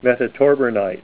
Name Pronunciation: Metatorbernite + Pronunciation Metatorbernite Image Images: Metatorbernite Comments: Green, square, tabular crystals of metatorbernite on matrix.